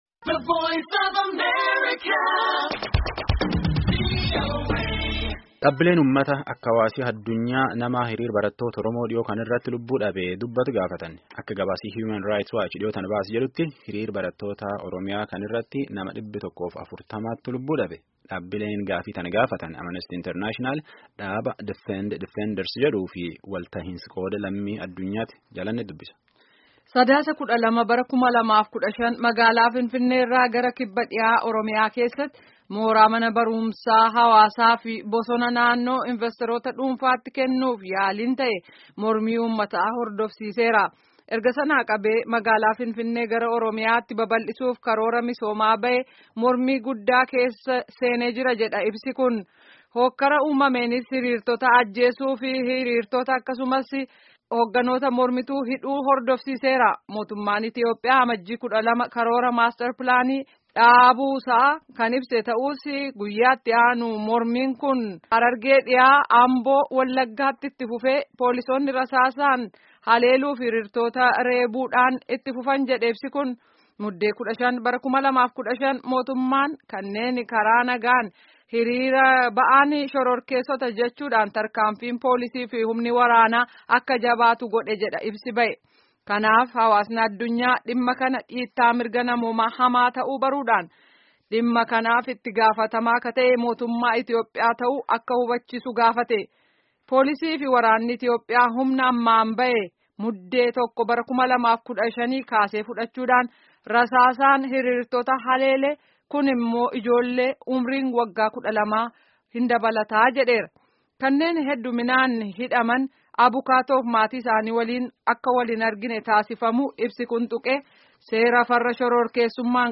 Gaaffii fi deebii Dr. Mararaa Guddinaa waliin geggeessame guutummaa isaa dhaggeeffadhaa